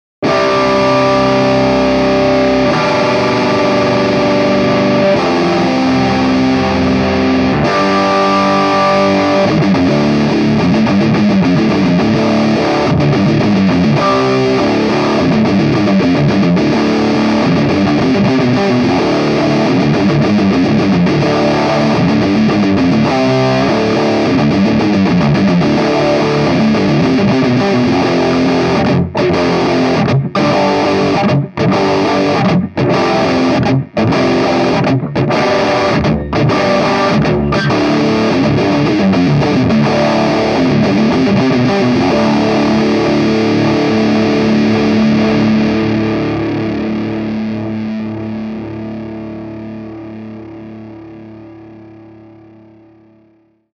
SG-Heavy-Riff-Micd-with-PPC412.mp3